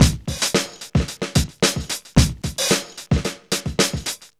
DOPE BEAT111.wav